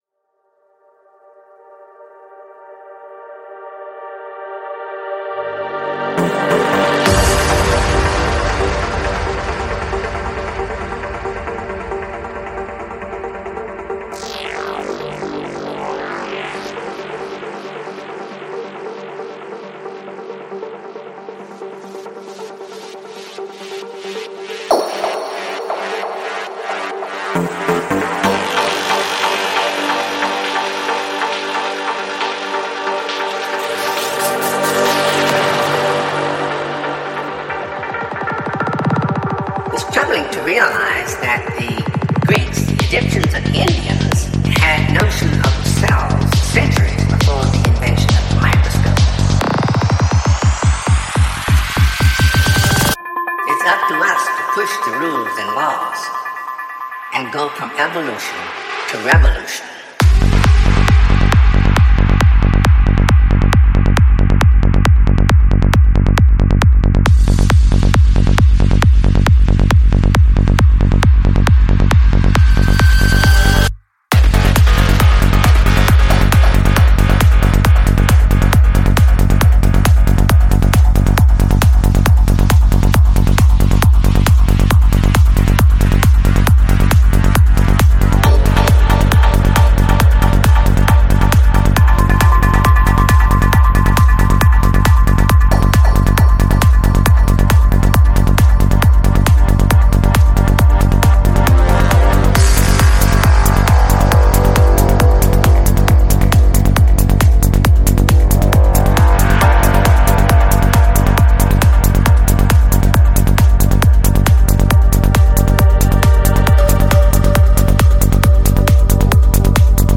Жанр: Electronic
Psy-Trance